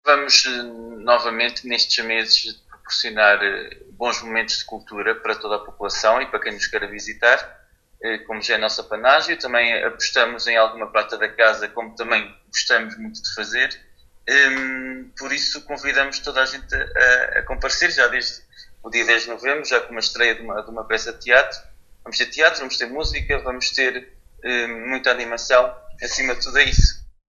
Paulo Marques, Presidente do Município de Vila Nova de Paiva, em declarações à Alive FM, deixa o convite.